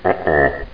00061_Sound_UH-OH.mp3